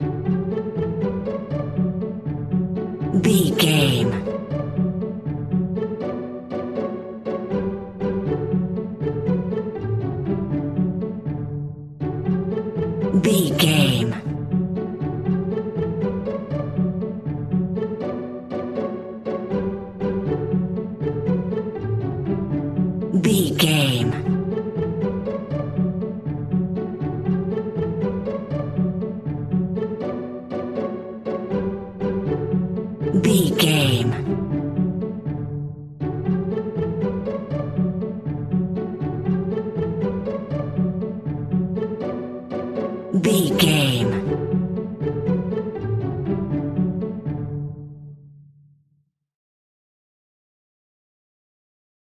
Pizzicato
Ionian/Major
D
Fast
nursery rhymes
childrens music